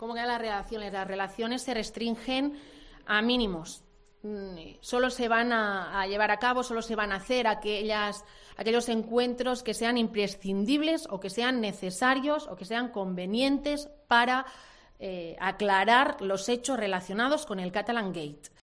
Patricia Plaja, portavoz del Govern anuncia que las relaciones con el Gobierno Central serán mínimas